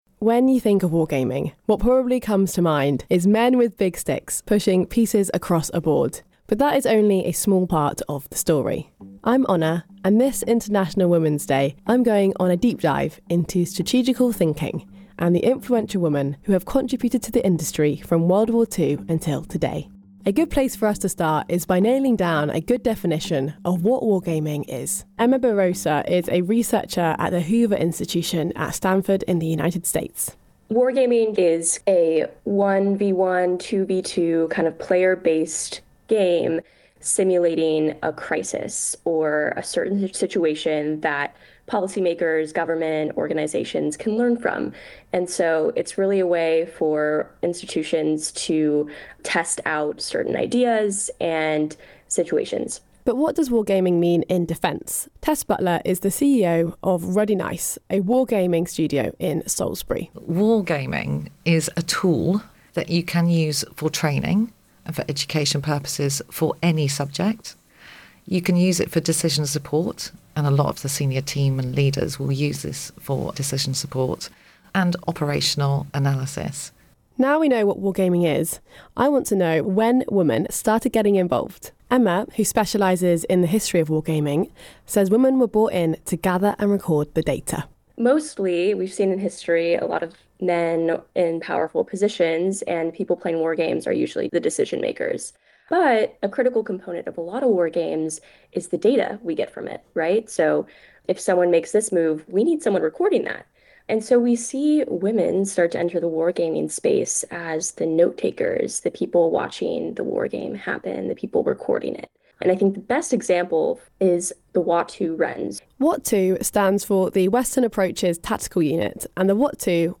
The programme includes interviews with survivors of the camp, poignant archive audio, and first-hand accounts written in 1945.